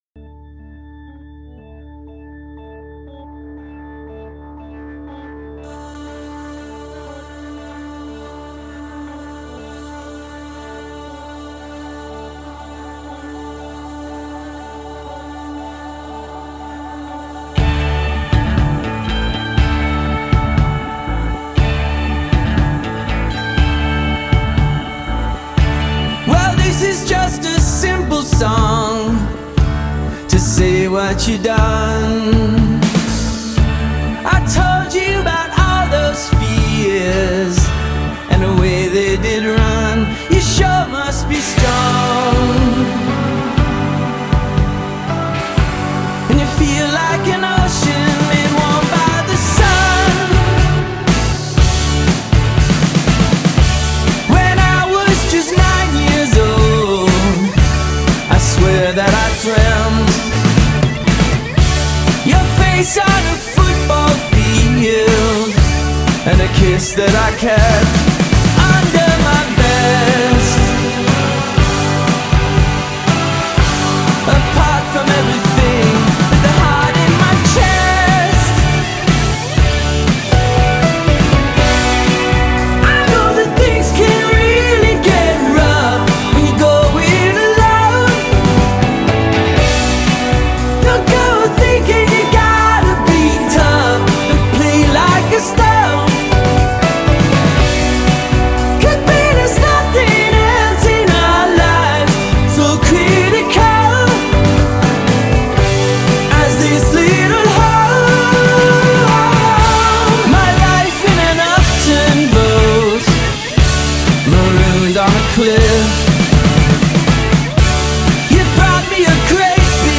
Both make adorable indie pop.